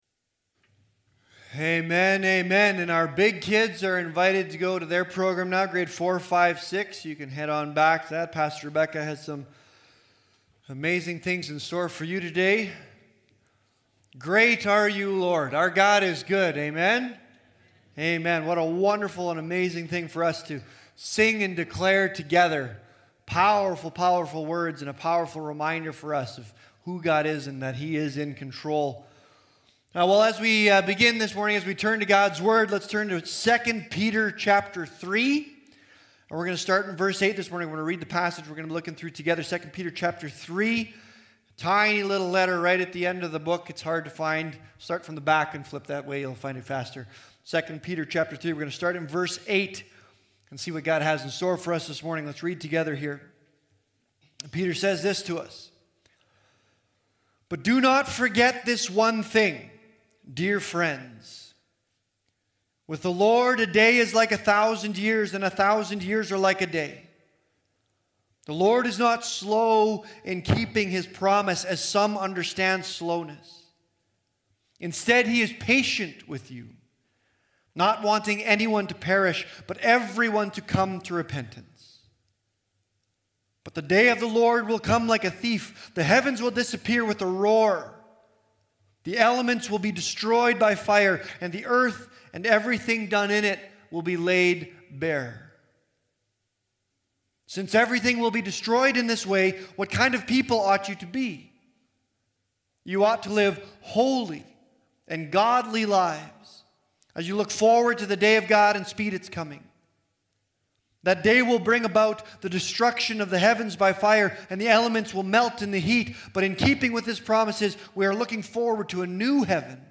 The Word Alive In Us Current Sermon So How Then Should We Live?